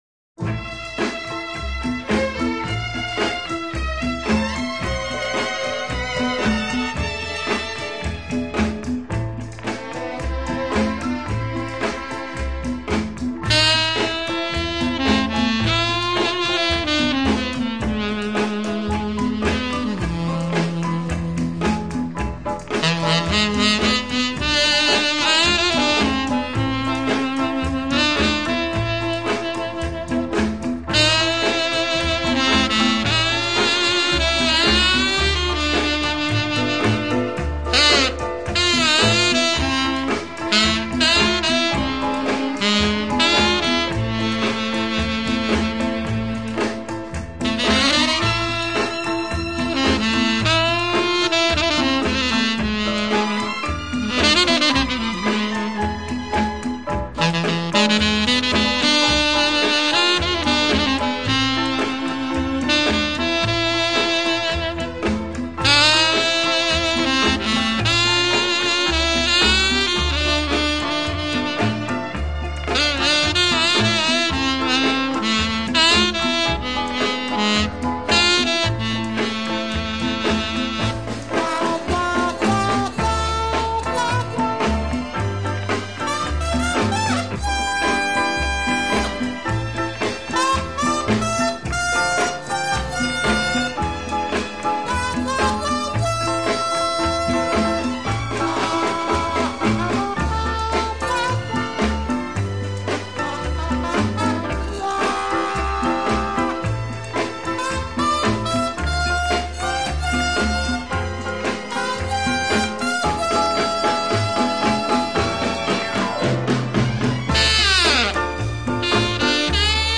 Жанр: Jazz, Instrumental, Easy Listening